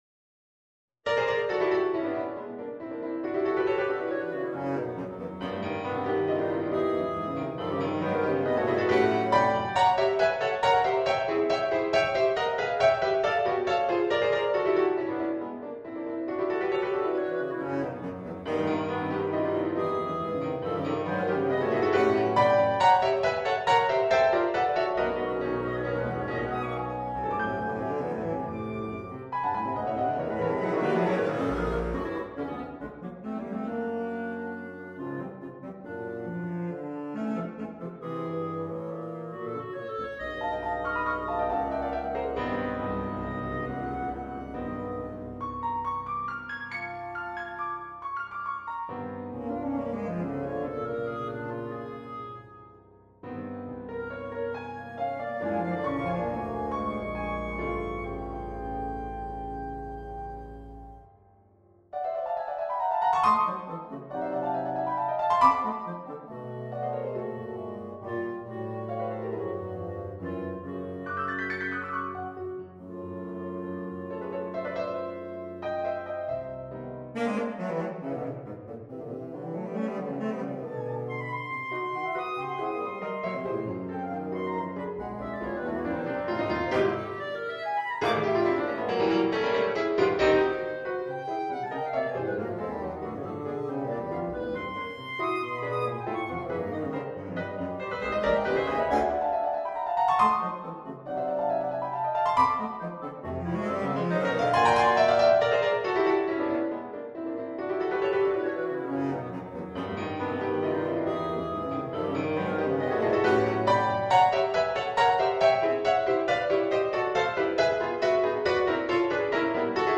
Clarinet, Basoon, Bass Saxophone + Piano
Music / Classical
The harmonies especially are really good